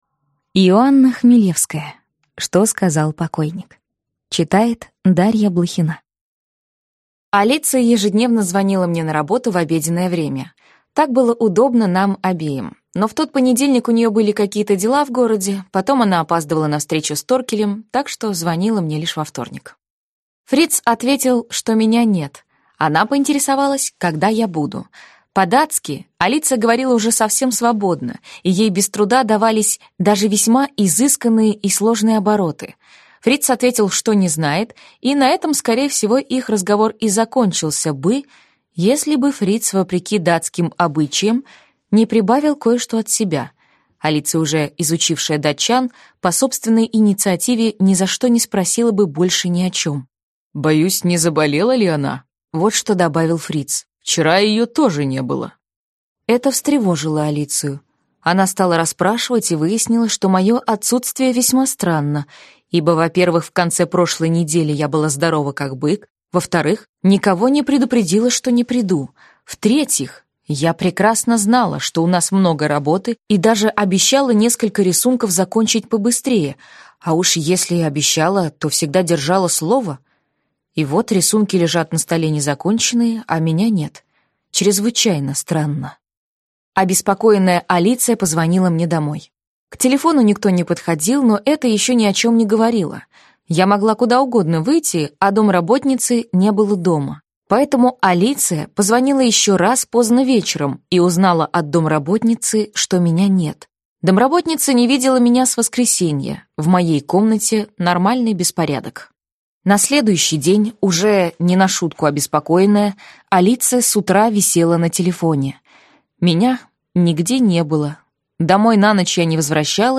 Аудиокнига Что сказал покойник | Библиотека аудиокниг